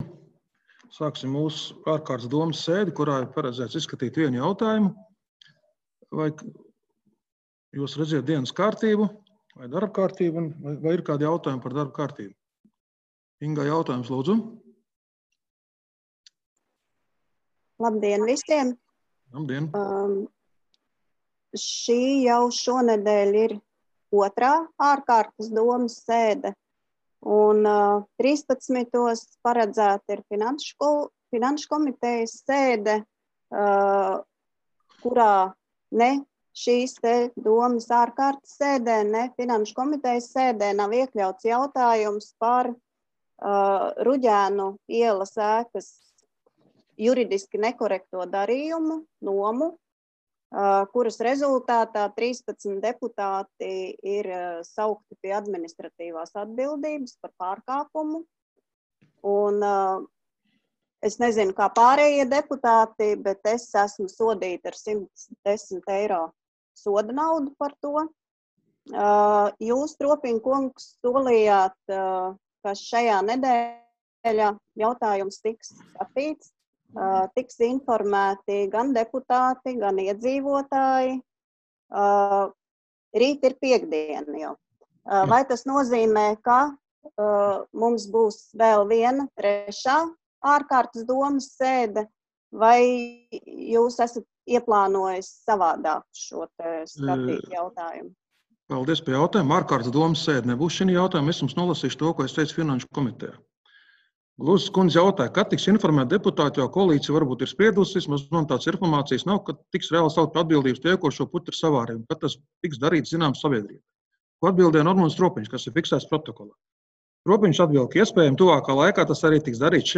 Balss ātrums Publicēts: 04.06.2020. Protokola tēma Domes sēde Protokola gads 2020 Lejupielādēt: 16.